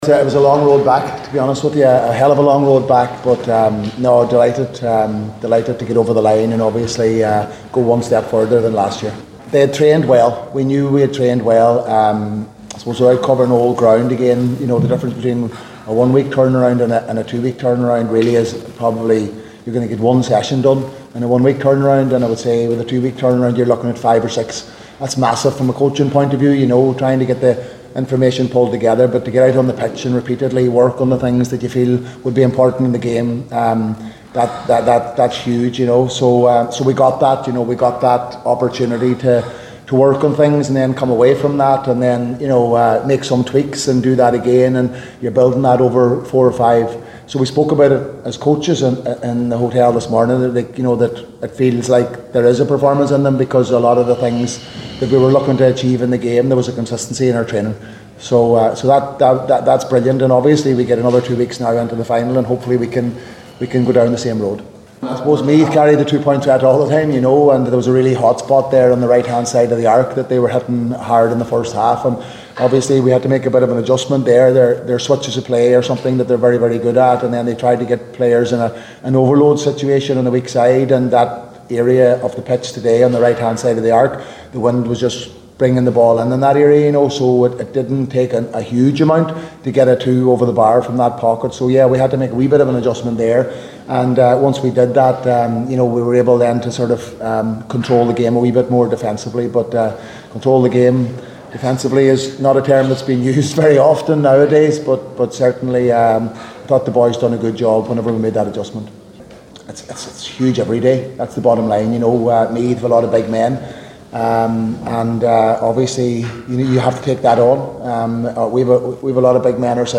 The Donegal boss said “it was a long road back” after today’s win…